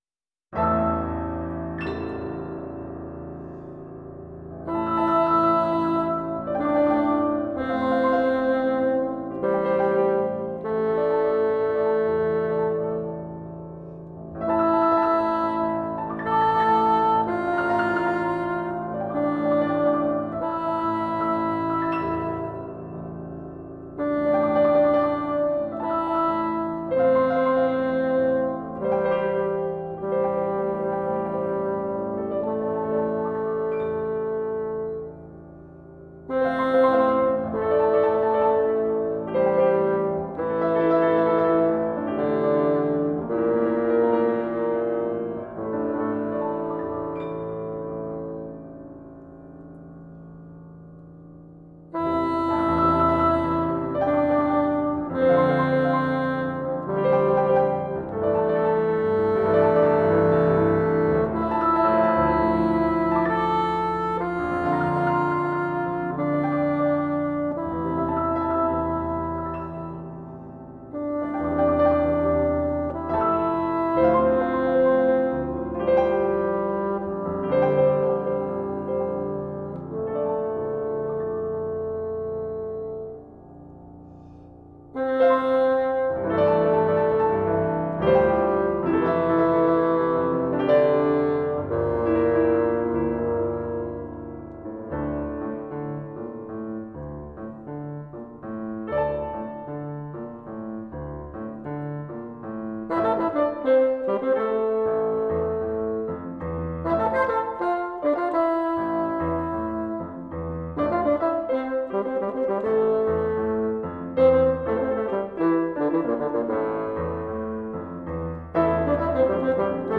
Here are some recordings of my recent performances.